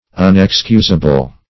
Search Result for " unexcusable" : The Collaborative International Dictionary of English v.0.48: Unexcusable \Un`ex*cus"a*ble\, a. Inexcusable.